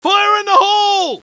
fire_in_hole.wav